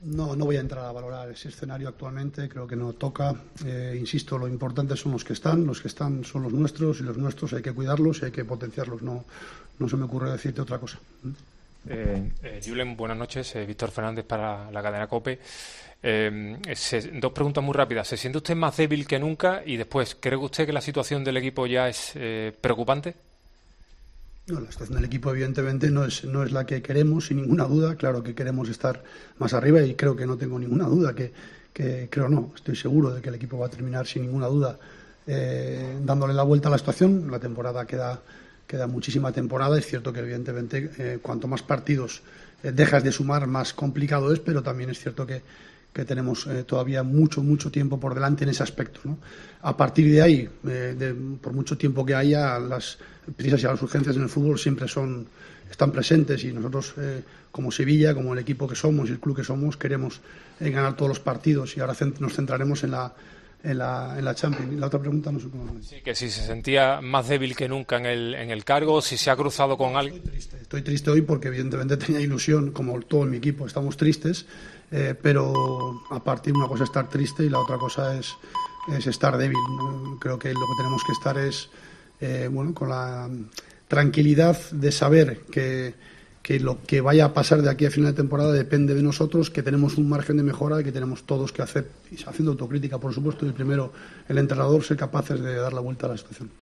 El técnico del Sevilla se mostró firme tras otra derrota en Liga del Sevilla y con su puesto más discutido que nunca: "Estoy seguro de que daremos la vuelta a la situación".
Julen Lopetegui, entrenador del Sevilla, afirmó tras la derrota por 0-2 ante el Atlético de Madrid que no piensa en su "futuro", aunque sigue cuestionado por los malos resultados de su equipo esta temporada, sino que está centrado "en recuperar jugadores y preparar bien el partido que nos toca", el del miércoles, de nuevo en casa, con el Borussia Dortmund en la 'Champions'.